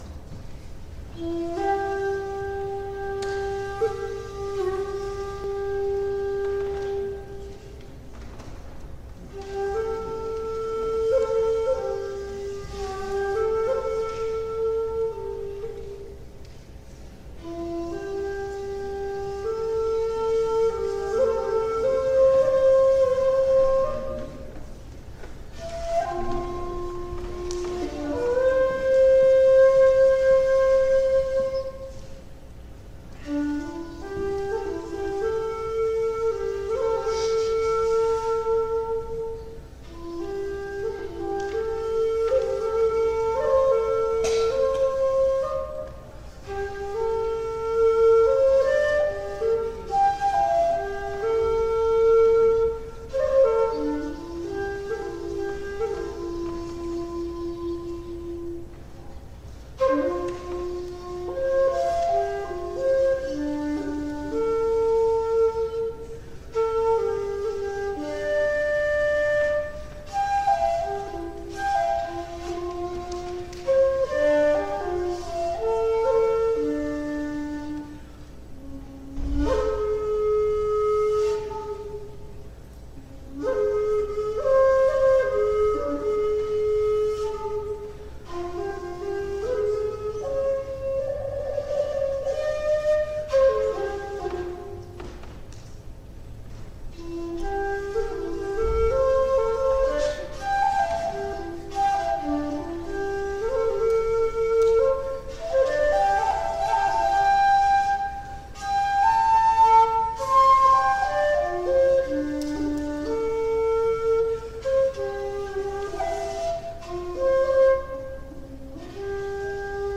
The Low Hills Echo for shakuhachi, 2023